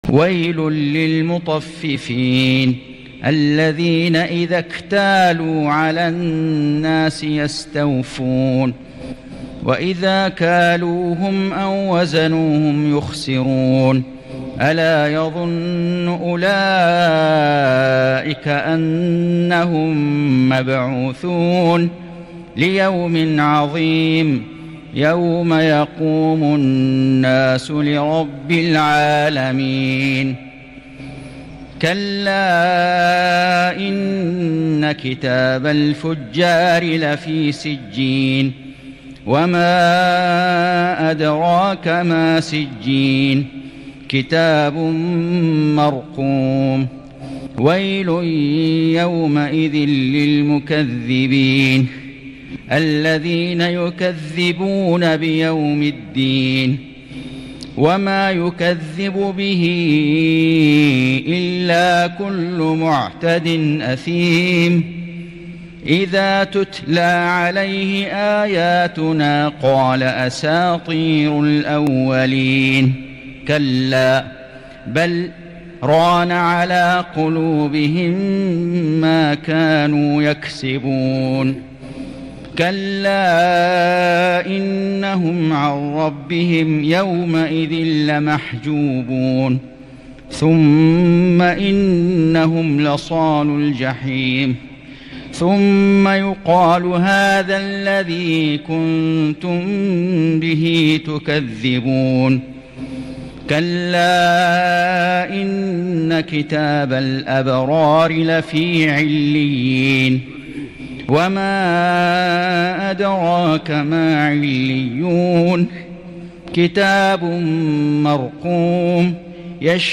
سورة المطففين > السور المكتملة للشيخ فيصل غزاوي من الحرم المكي 🕋 > السور المكتملة 🕋 > المزيد - تلاوات الحرمين